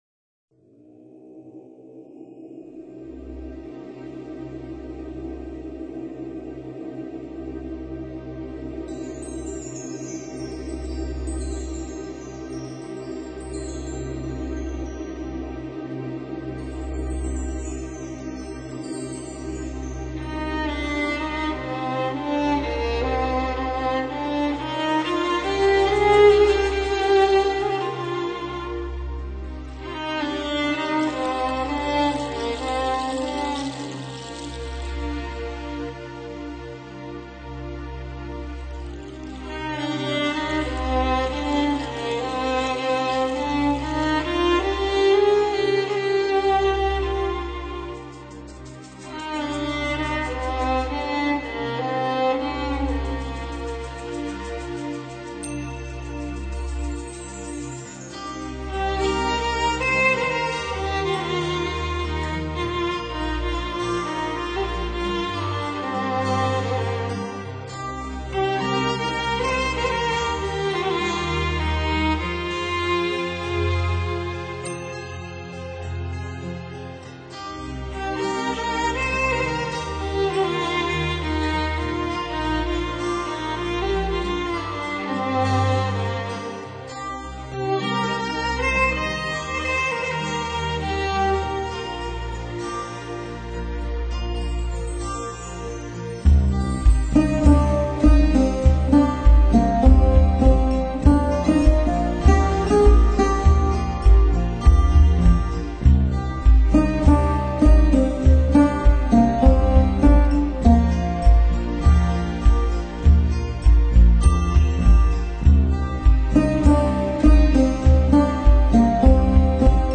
音乐舒缓，节奏轻快